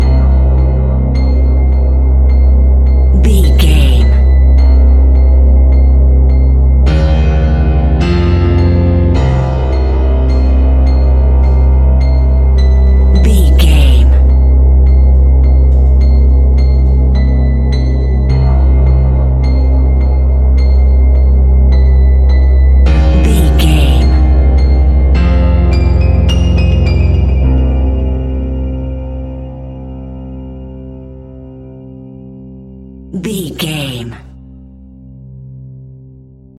In-crescendo
Thriller
Aeolian/Minor
ominous
suspense
eerie
instrumentals
horror music
Horror Pads
horror piano
Horror Synths